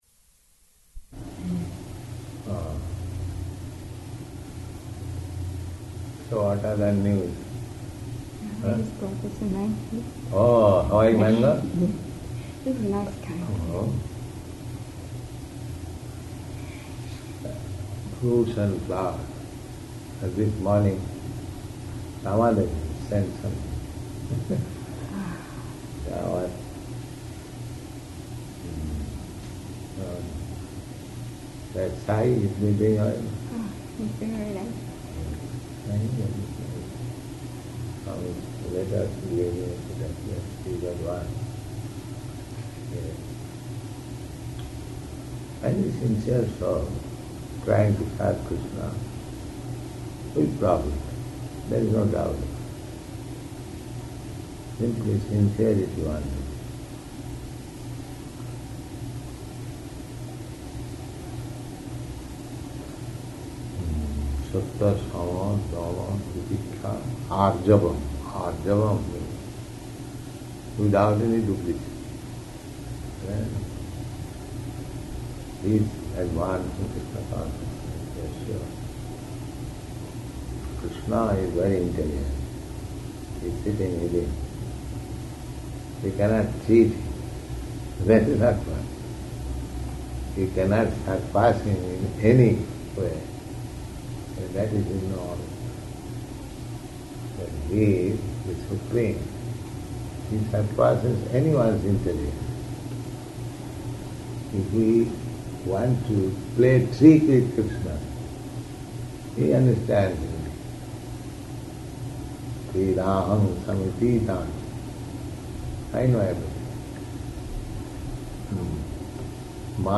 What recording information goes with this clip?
-- Type: Conversation Dated: June 30th 1971 Location: Los Angeles Audio file